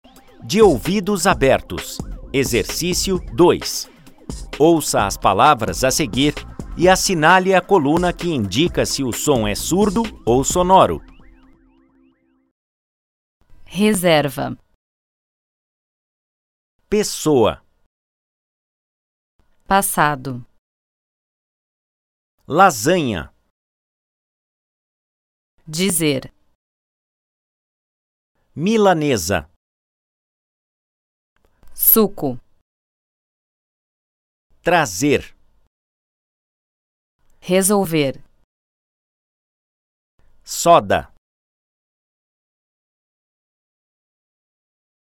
Pronúncia surdo e sonoro palavras.mp3